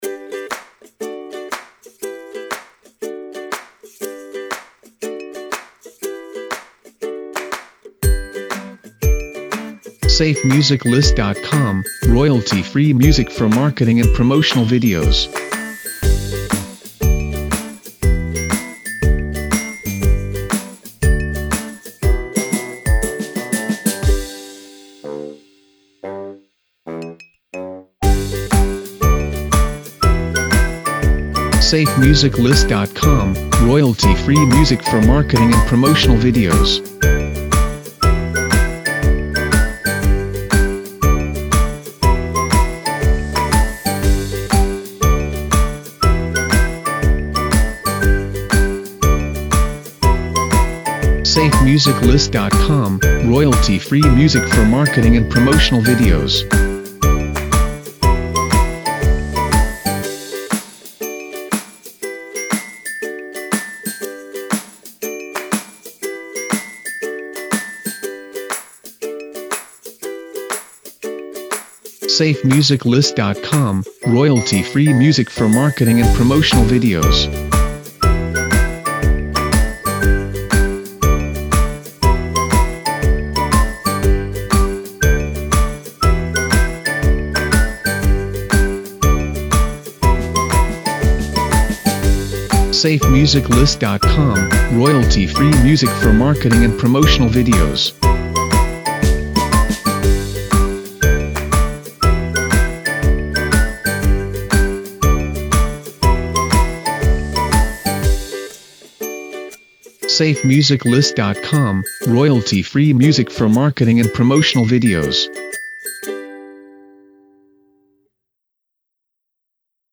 2:00 120 bpm
BPM: 120